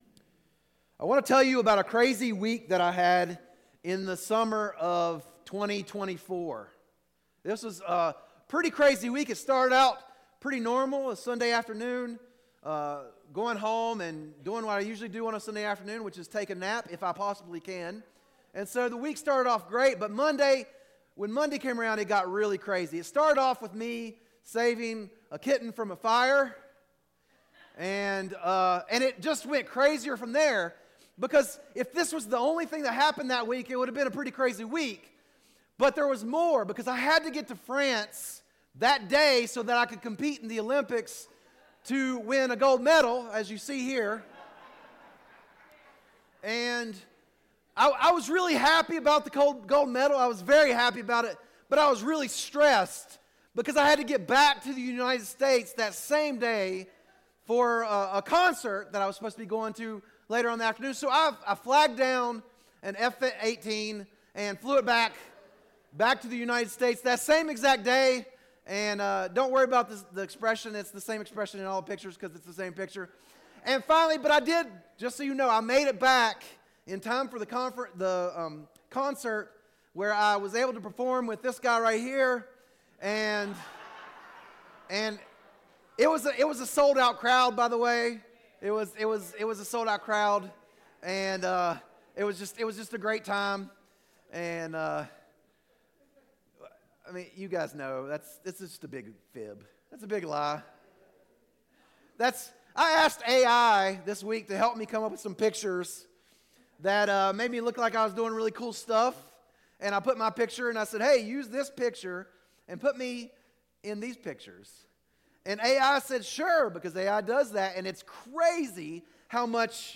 Sermons | Scranton Road Bible Church
Easter Sunday Service - Numb